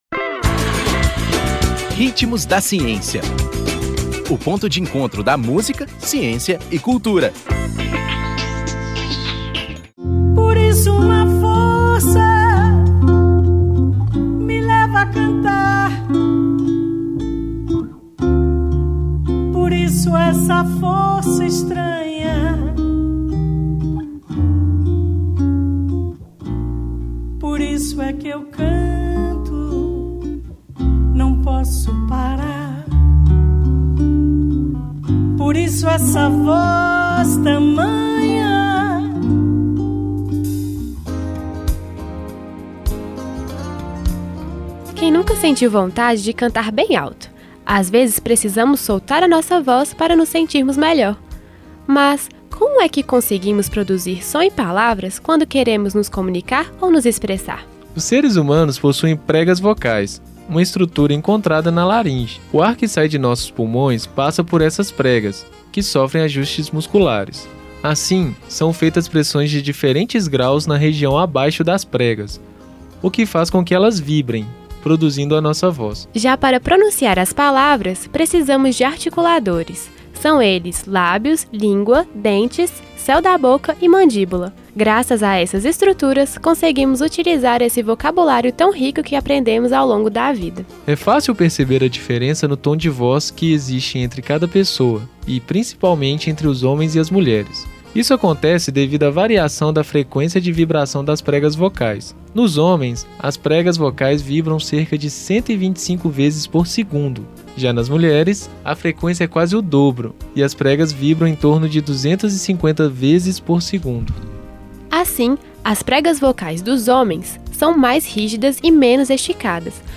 Intérprete: Gal Costa